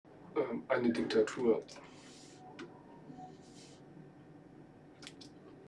Standort der Erzählbox:
MS Wissenschaft @ Diverse Häfen
Standort war das Wechselnde Häfen in Deutschland.